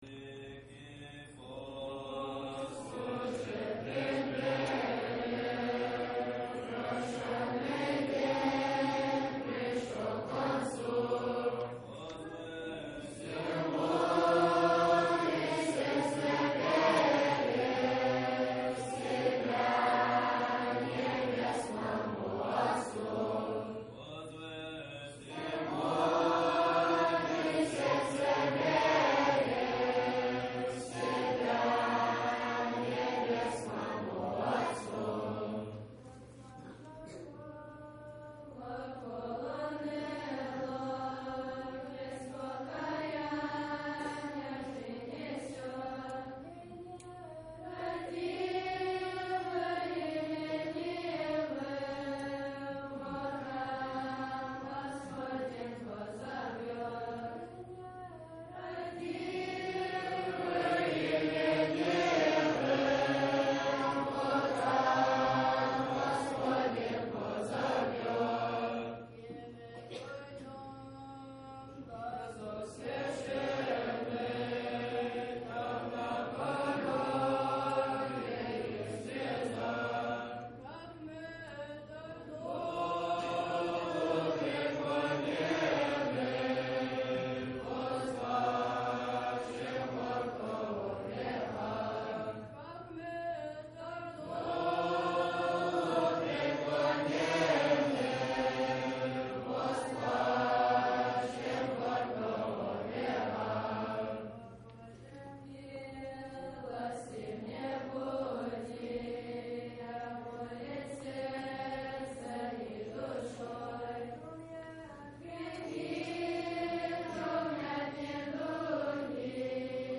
Choir sings. . .
Choir.MP3